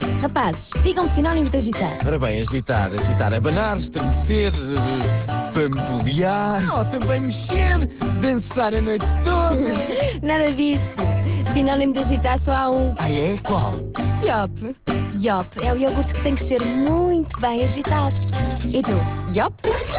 No dia 3 de Outubro estreou no meio rádio uma campanha da marca Yoplait com dois spots diferentes (